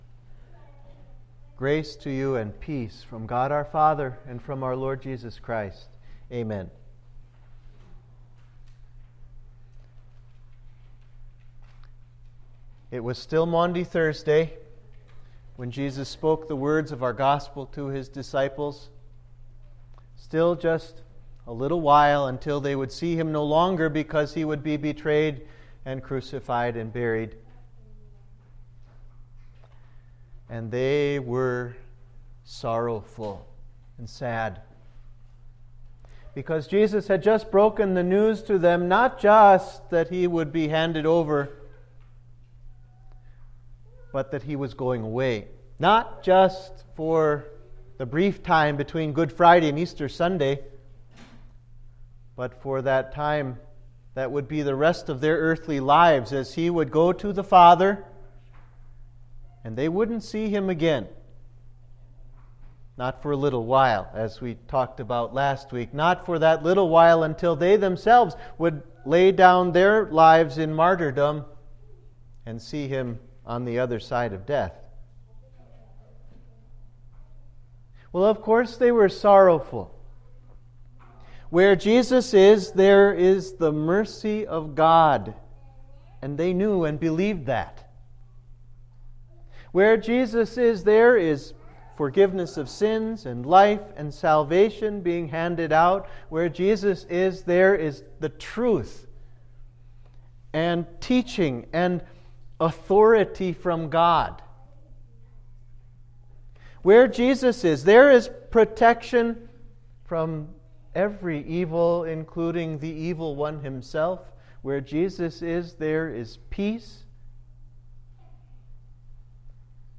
Sermon for Cantate – Fifth Sunday of Easter